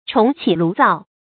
重起爐灶 注音： ㄓㄨㄙˋ ㄑㄧˇ ㄌㄨˊ ㄗㄠˋ 讀音讀法： 意思解釋： 謂事情遭受挫折后，再從頭做起。